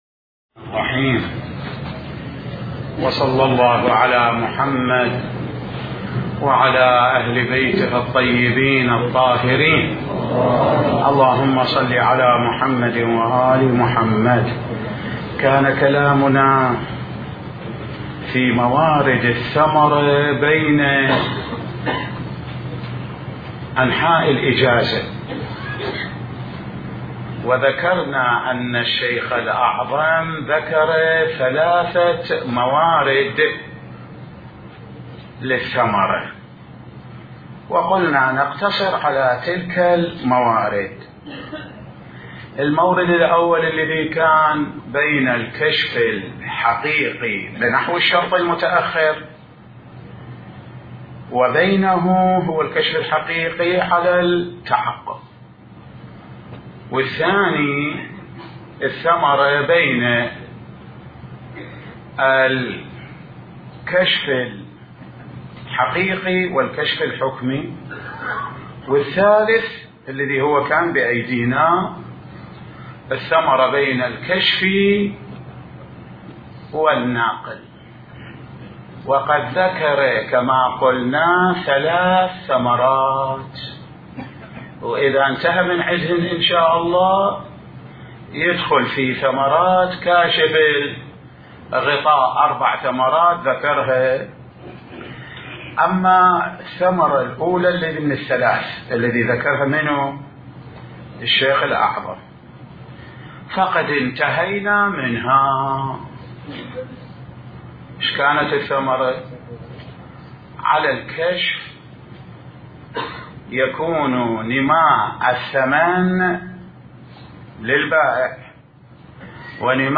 بحث الفقه